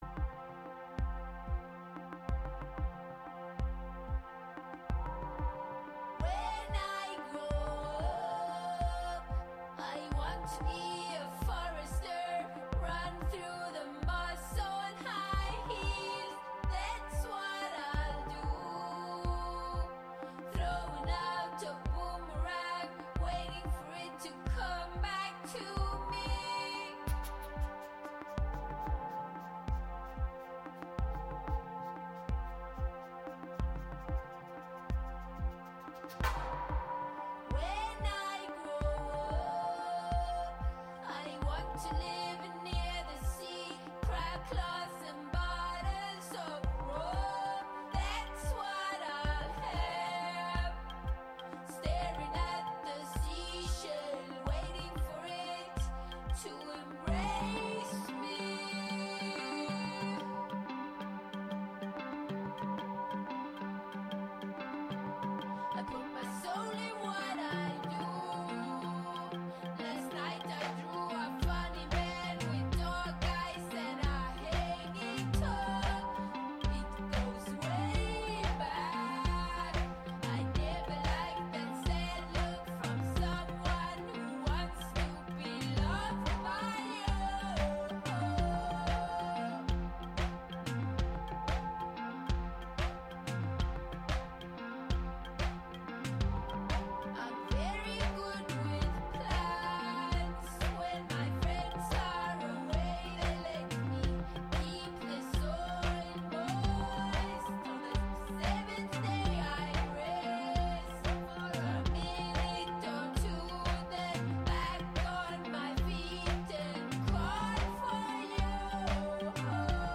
Show includes local WGXC news at beginning, and midway through. Get yr weekly dose of music appreciation, wordsmithing, and community journalism filtered through the minds and voices of the Youth Clubhouses of Columbia-Greene, broadcasting out of the Catskill Clubhouse, live on Fridays as part of All Together Now! and rebroadcast Saturday at 4 a.m. and Sunday at 10 a.m.